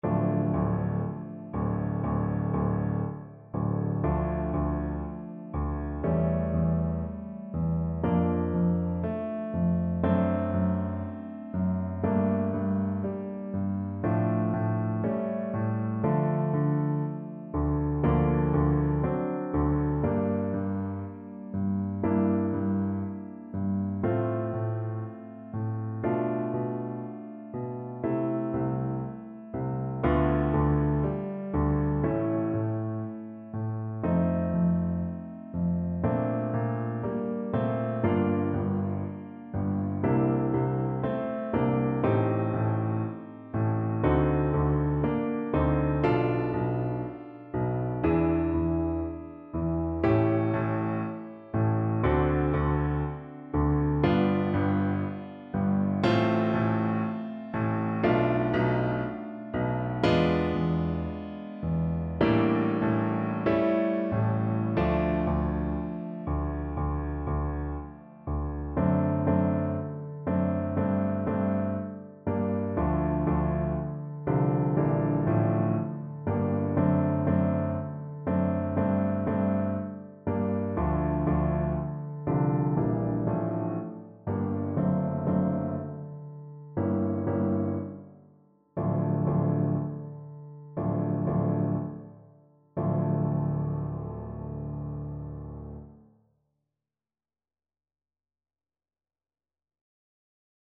Tuba version
2/2 (View more 2/2 Music)
D3-D4
Molto Moderato = 60
D minor (Sounding Pitch) (View more D minor Music for Tuba )
Classical (View more Classical Tuba Music)